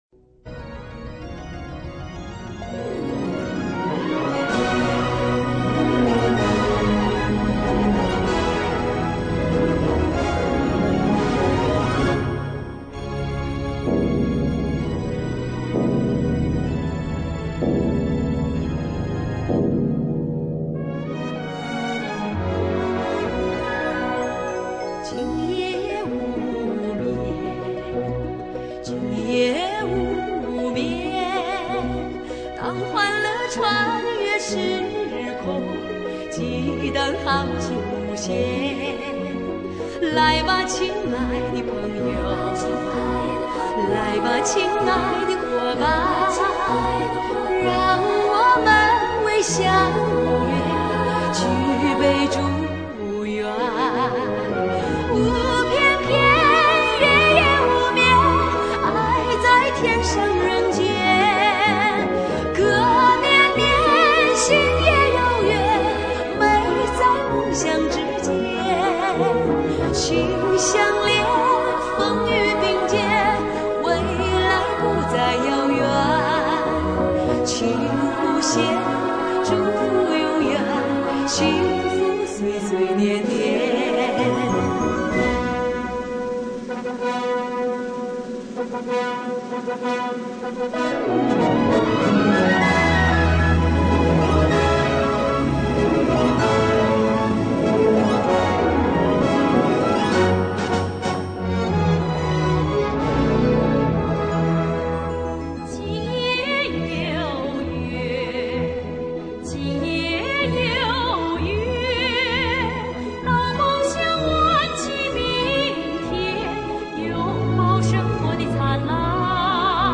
美声唱法
民族唱法
通俗唱法
三位获奖歌手联袂演唱了这首